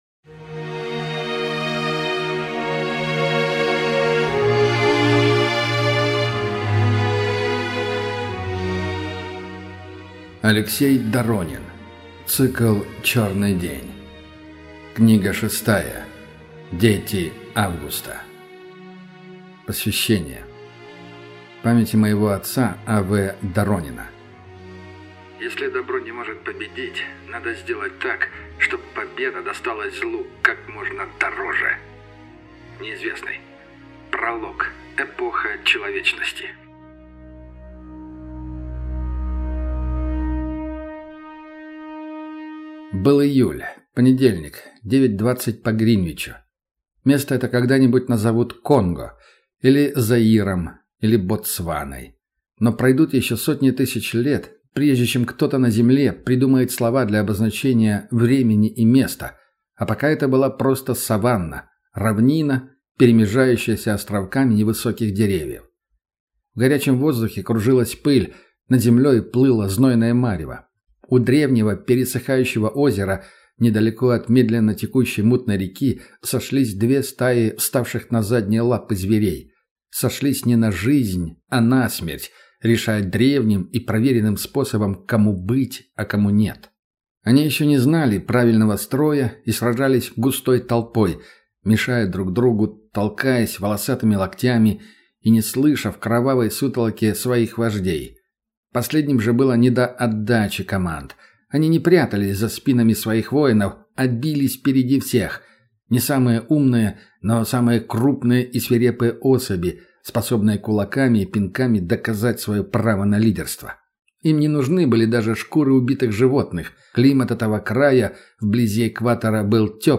Аудиокнига Дети августа | Библиотека аудиокниг